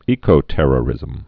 (ēkō-tĕrə-rĭzəm, ĕkō-)